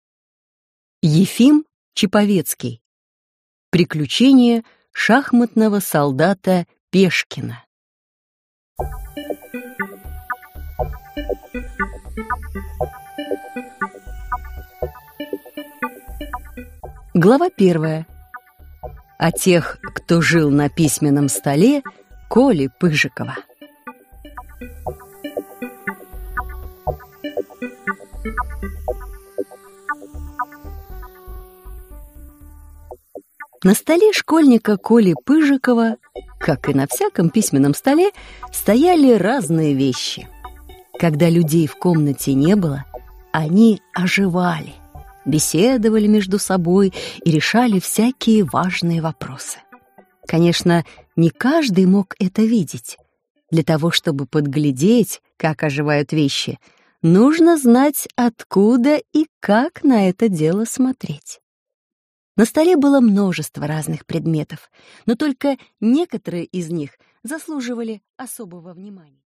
Аудиокнига Приключения шахматного солдата Пешкина | Библиотека аудиокниг